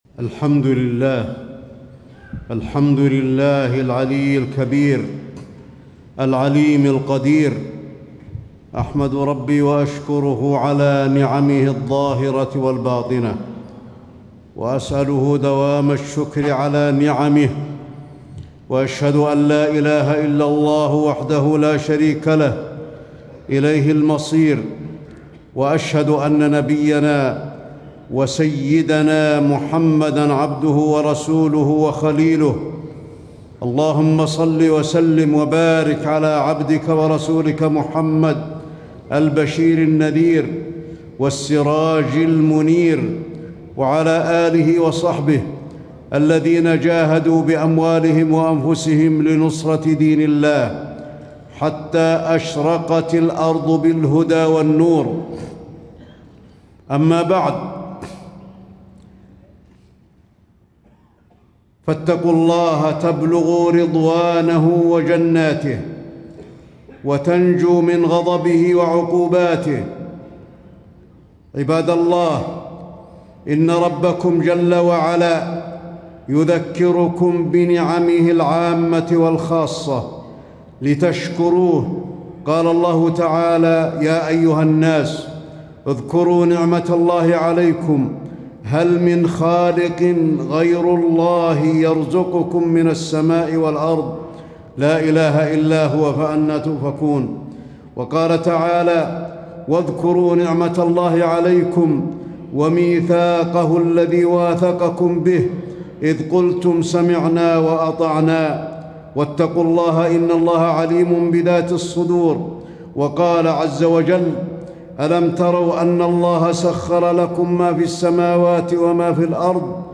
تاريخ النشر ١٧ ربيع الثاني ١٤٣٦ هـ المكان: المسجد النبوي الشيخ: فضيلة الشيخ د. علي بن عبدالرحمن الحذيفي فضيلة الشيخ د. علي بن عبدالرحمن الحذيفي فضل الشكر وثواب الشاكرين